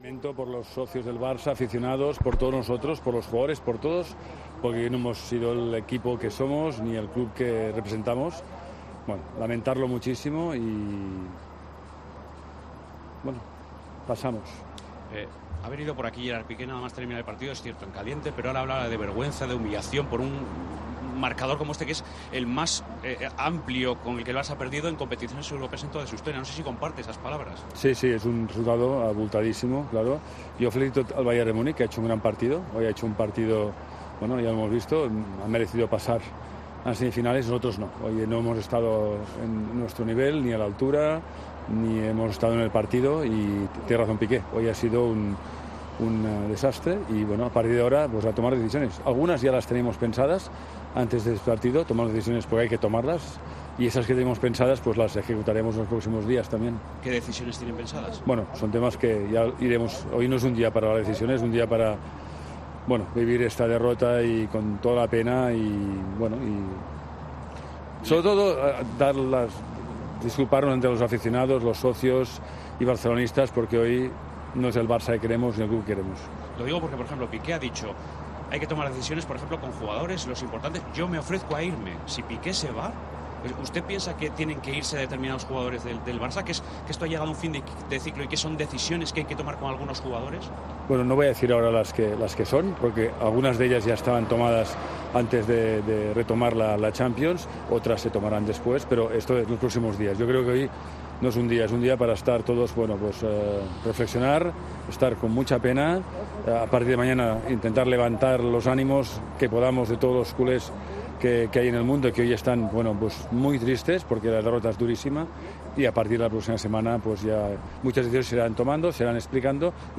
El presidente del Barcelona ha asegurado en el micrófono de Movistar que “tiene razón Piqué, no hemos estado a la altura”.